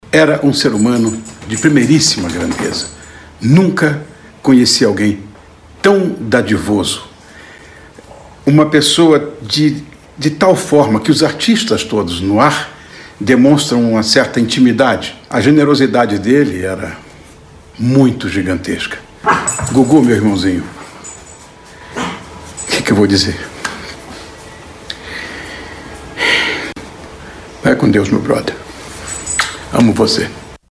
O cantor e apresentador Ronnie Von disse ter perdido um irmão e se emocionou ao falar de Gugu.
Cantor e apresentador Ronnie Von